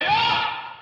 My Tears Vox.wav